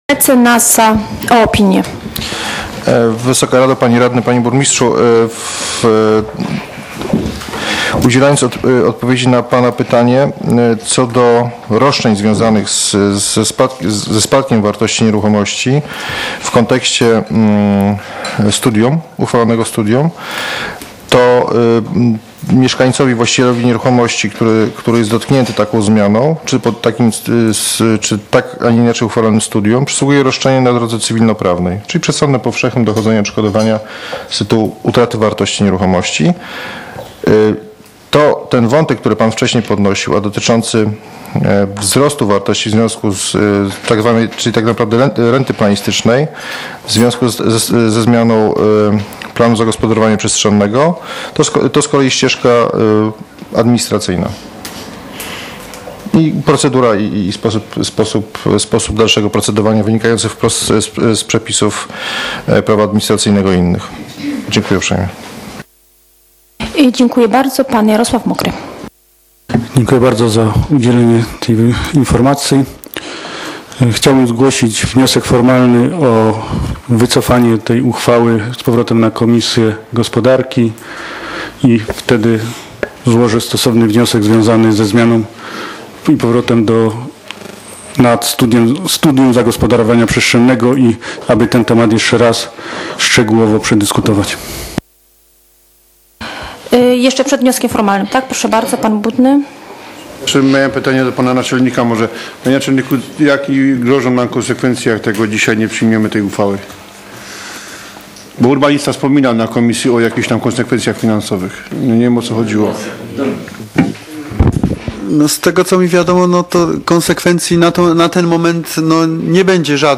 z obrad III sesji Rady Miejskiej w Bieruniu, która odbyła się w dniu 31.03.2016 r.